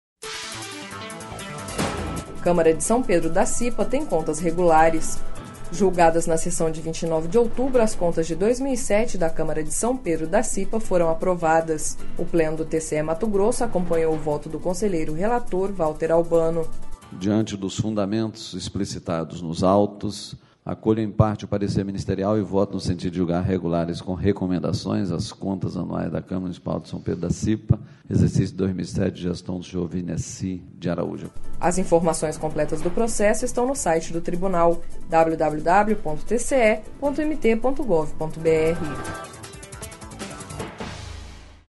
Sonora: Valter Albano – conselheiro do TCE-MT